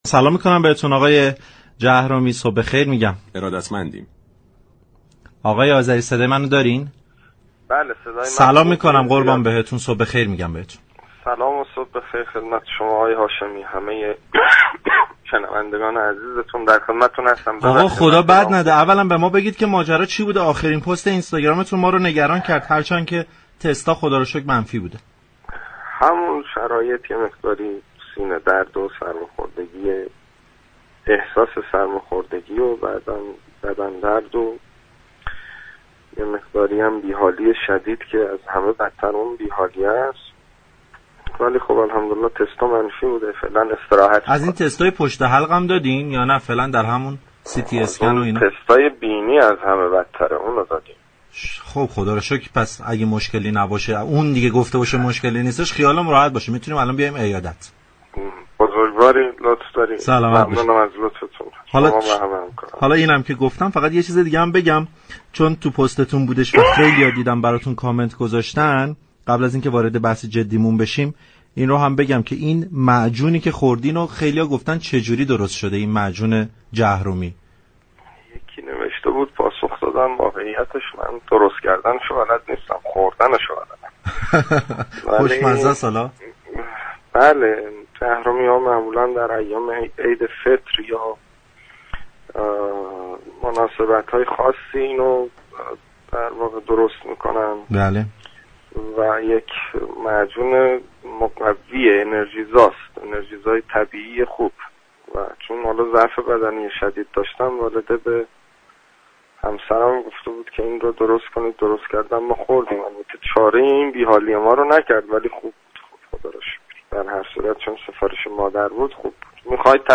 وزیر ارتباطات كشور علی‌رغم بیماری و سرفه‌های بسیار، دعوت پارك‌شهر رادیو تهران را پذیرفته و برای شفاف‌سازی برخی از اتفاقات اخیر در حوزه‌ی اینترنت با خبرنگار این مجله‌ی صبحگاهی گفتگو كرد.